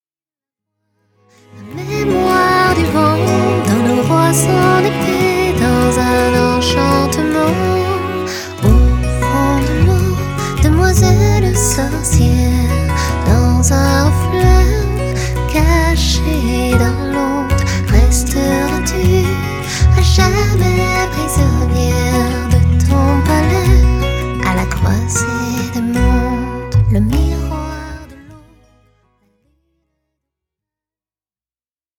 harpiste chanteuse